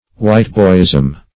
Whiteboyism \White"boy`ism\, n. The conduct or principle of the Whiteboys.